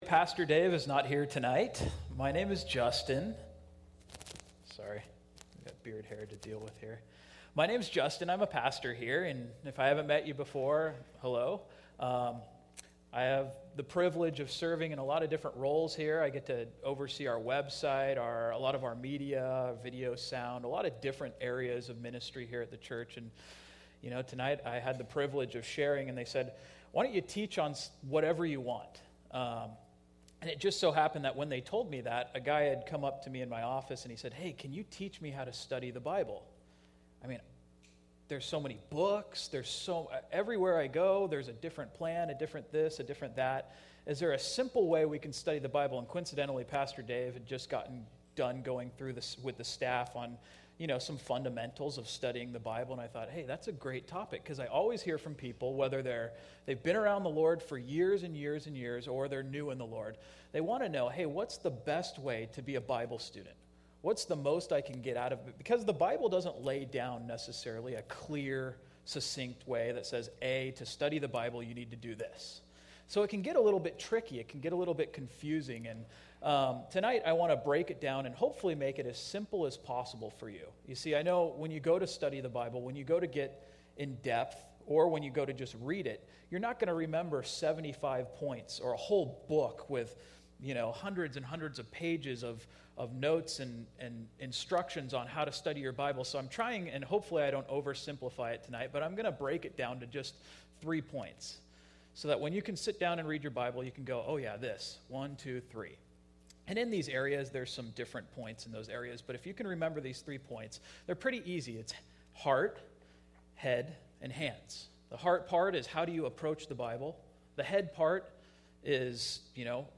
Teachings - The Truth Alive